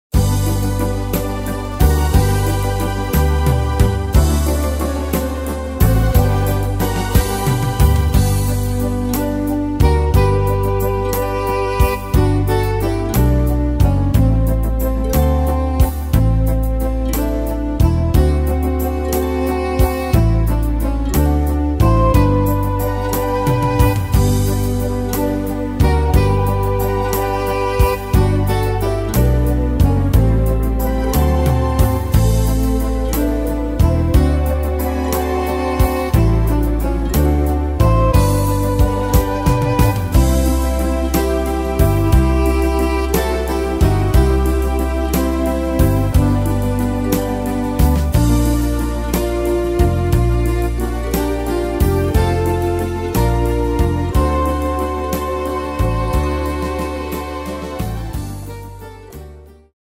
Tempo: 180 / Tonart: G-Dur